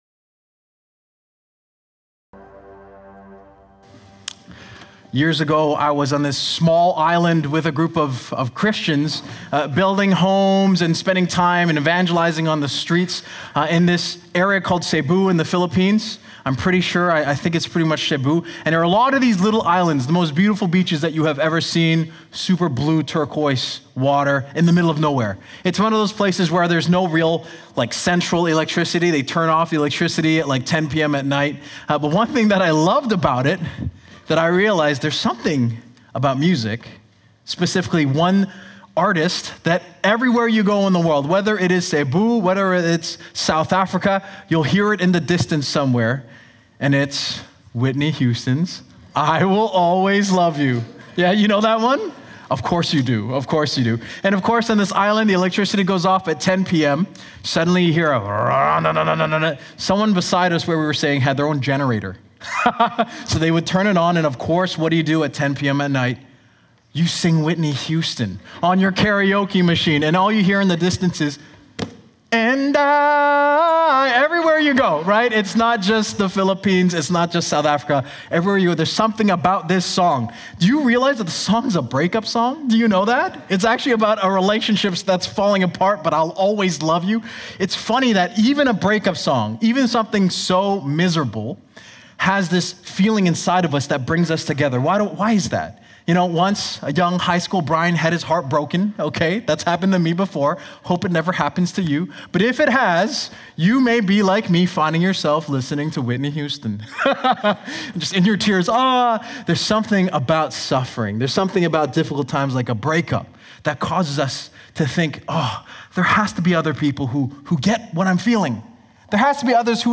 shared a powerful and hope-filled message